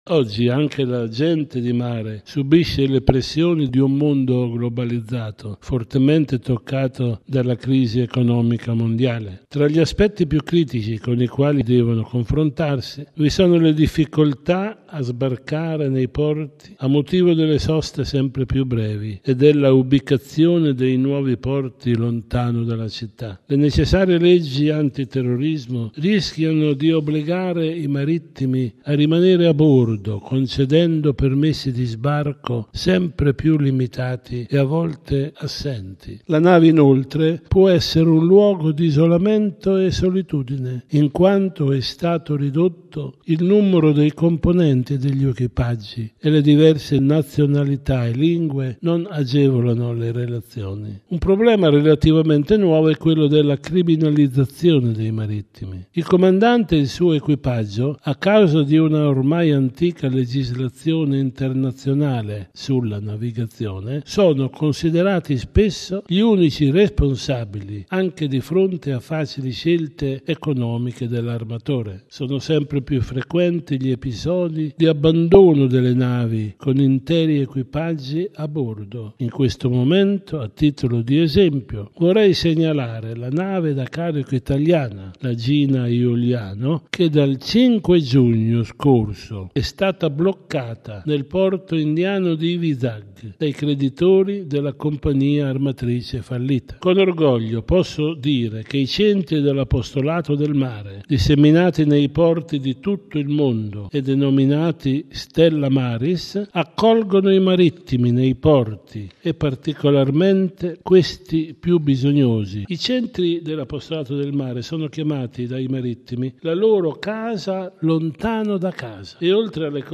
ne ha parlato con il cardinale Antonio Maria Vegliò, presidente del dicastero che organizza il Congresso: